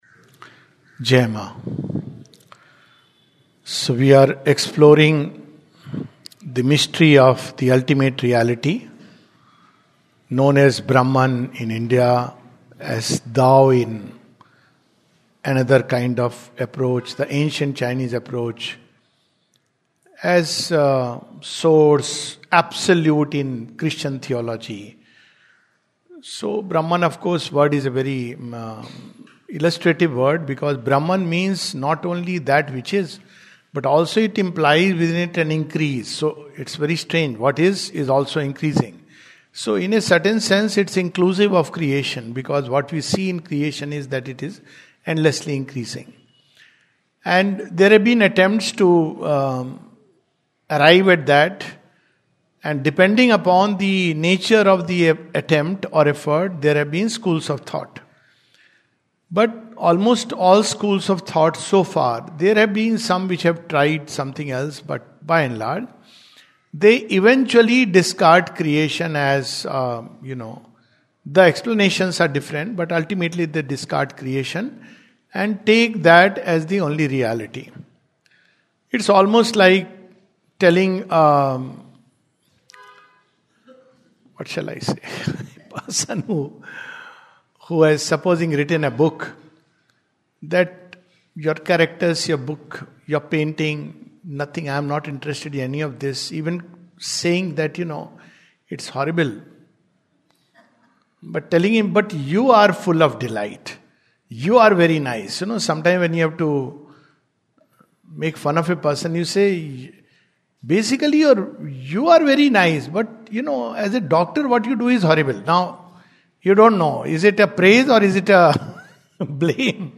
The Life Divine, 26th February 2026, Session # 06-11 at Sri Aurobindo Society, Pondicherry - 605002, India. We continue with Chapter 5 of Book Two of The Life Divine.